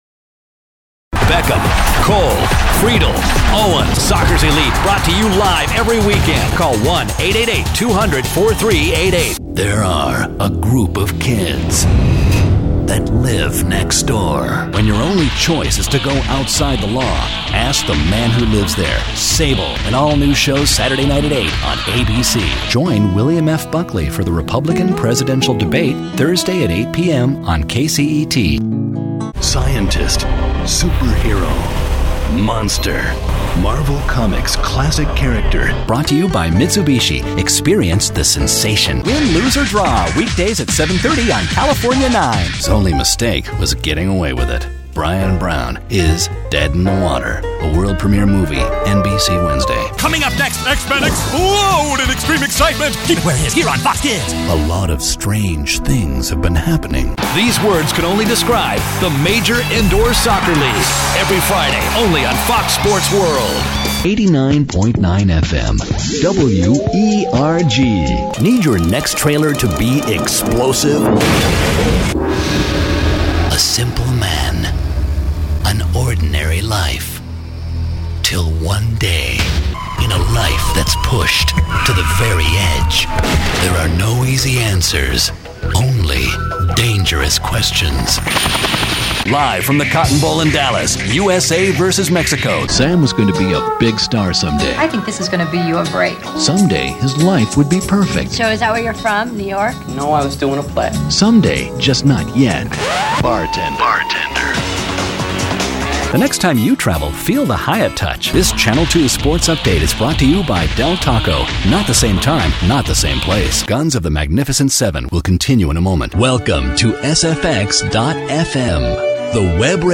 Promos
Voice Over